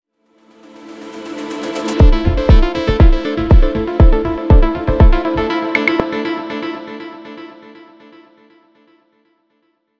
Podcast Überleitung zum nächsten Abschnitt 1
Podcast-Transition-1.wav